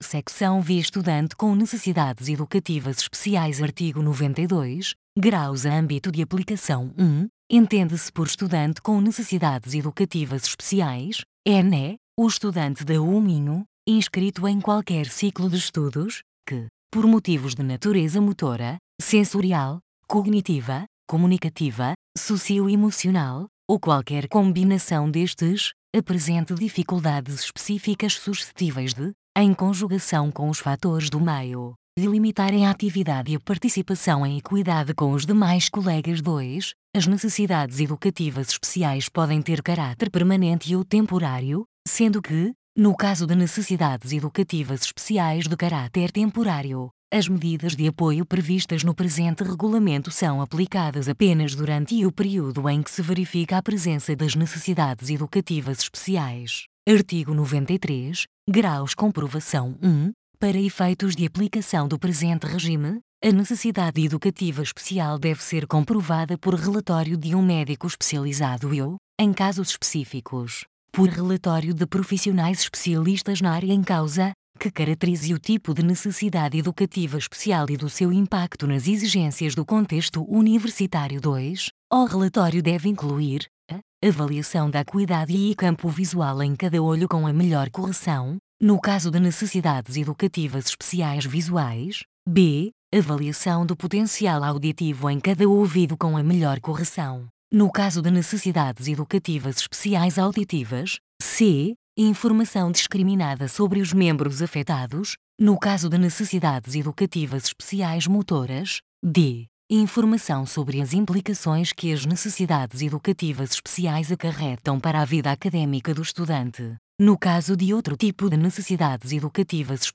Som - leitura em voz alta